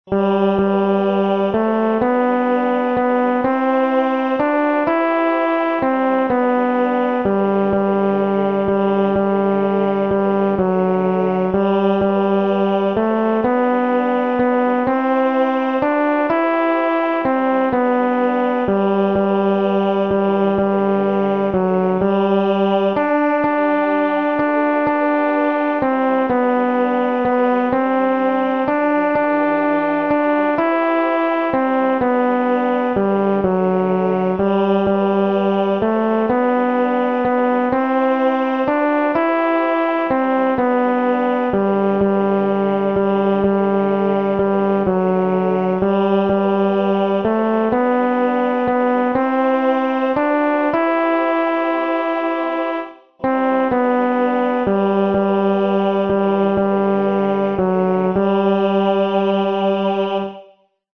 hymn J81 arranged Hawryluk